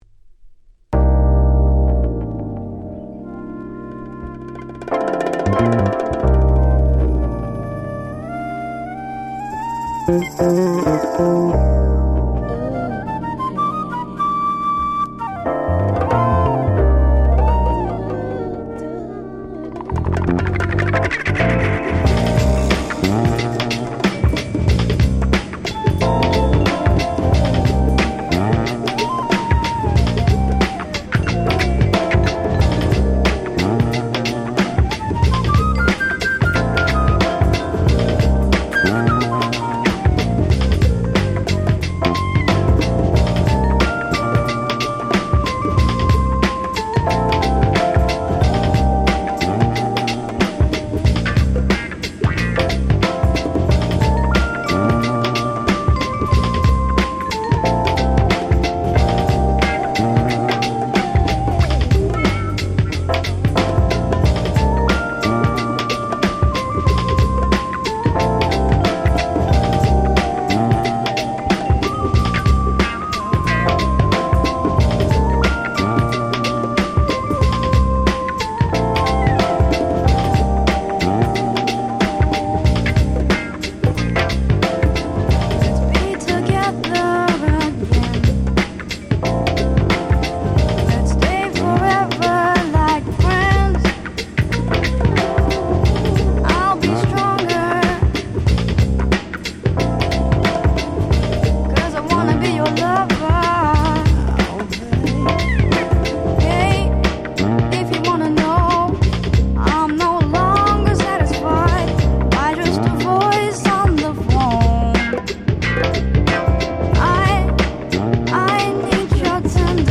97' Nice R&B !!
Japanese R&B